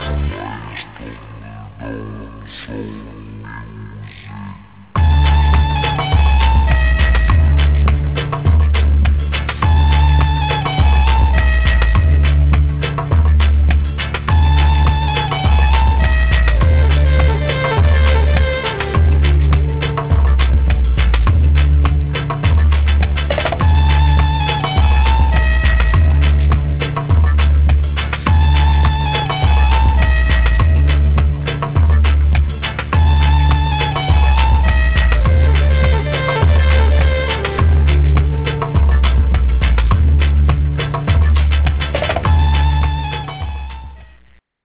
Kontrabass und Vibraphon.